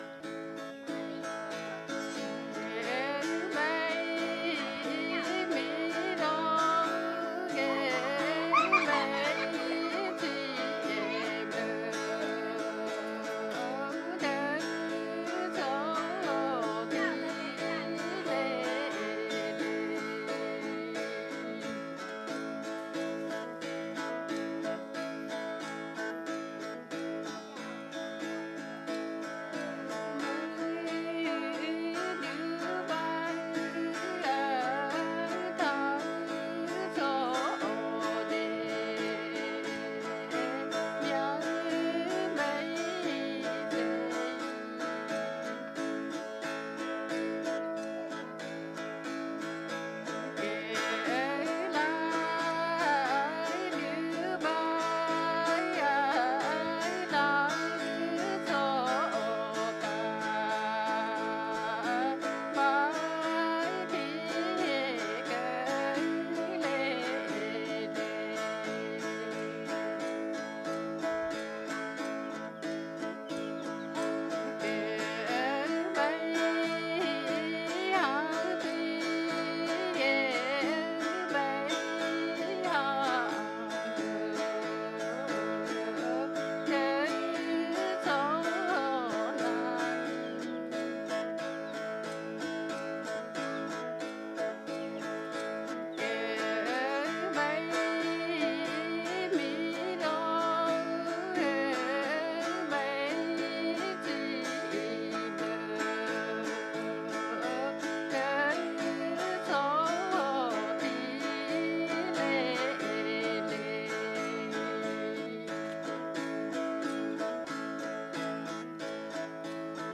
The singer accompanies herself on a 4 string guitar. 1.9MB
Track 12 Padong song (female singer).mp3